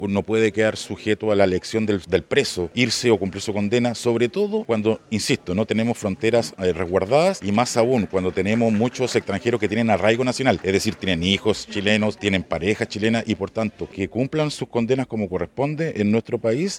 Muy por el contrario, el Diputado independiente de Los Lagos, Héctor Ulloa, afirma que es preferible que cumplan la pena en el país cuando las fronteras no están bien resguardadas.